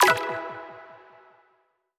button-generic-select.wav